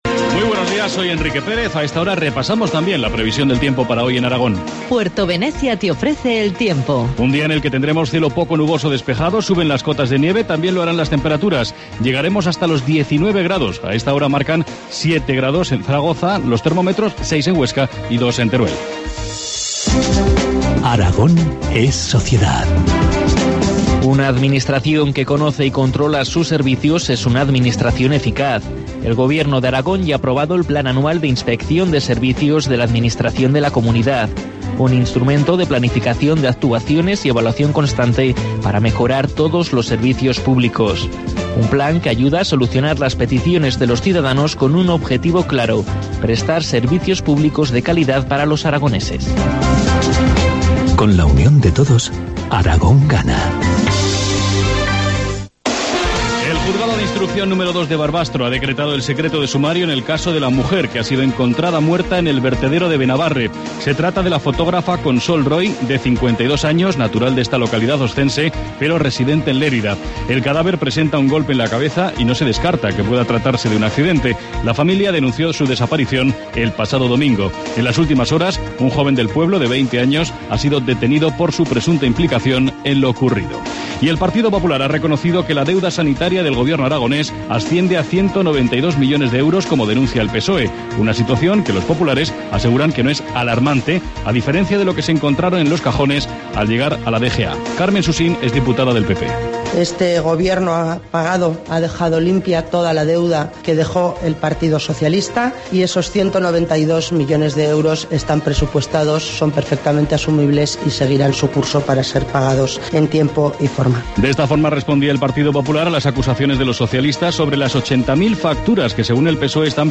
Informativo matinal, miércoles 3 de abril, 8.25 horas